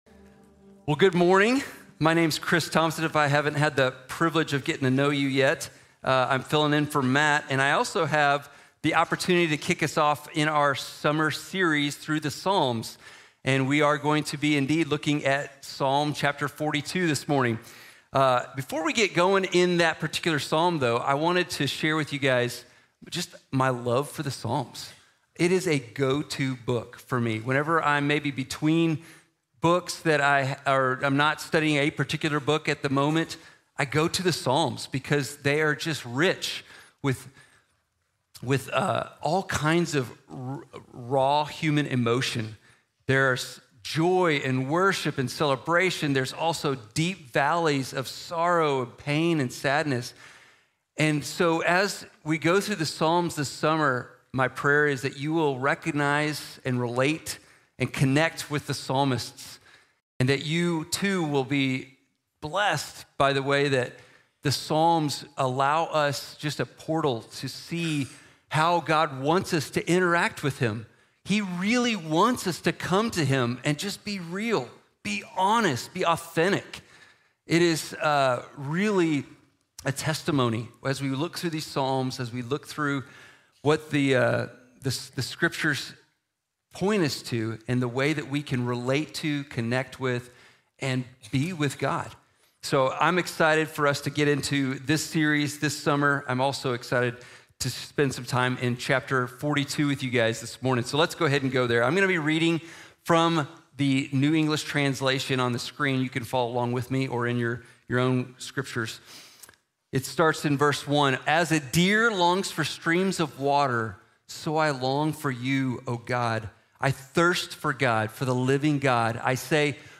Depression | Sermon | Grace Bible Church